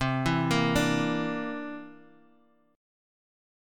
C9 Chord
Listen to C9 strummed